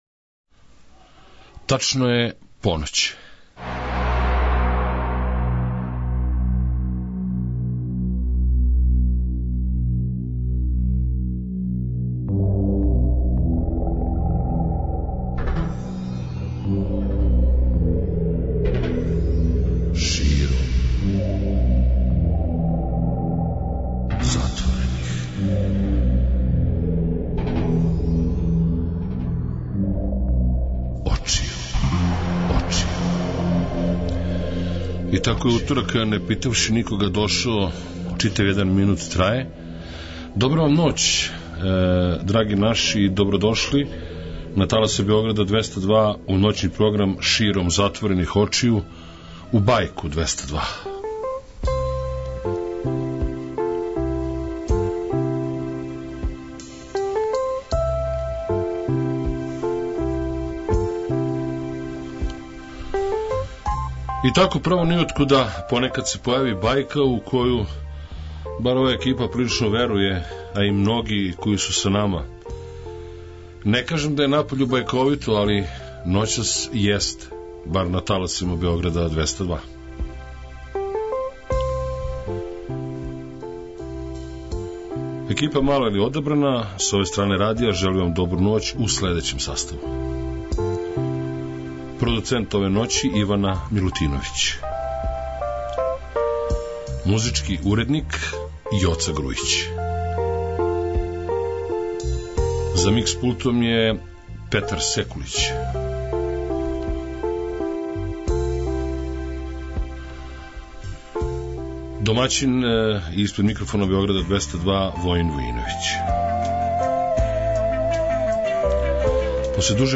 Noćni program Beograda 202 Širom zatvorenih očiju donosi u noći ponedeljka na utorak Bajku 202.